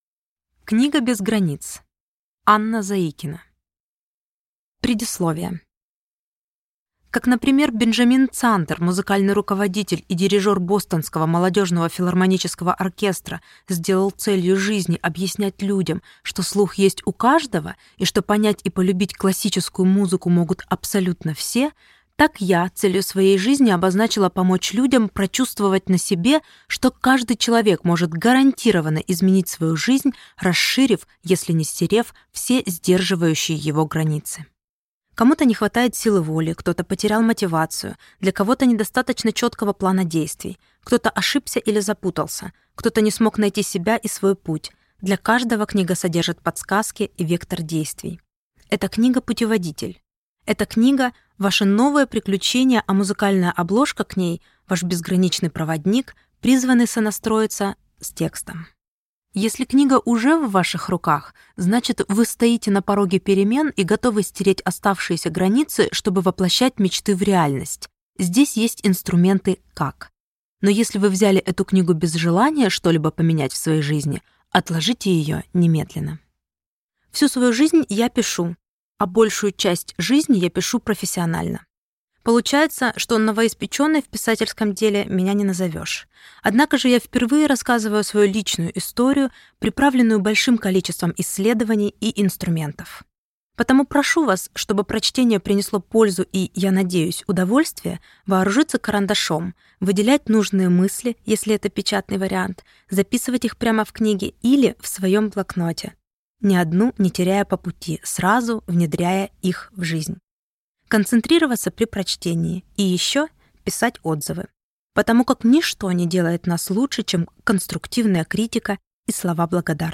Aудиокнига Книга без границ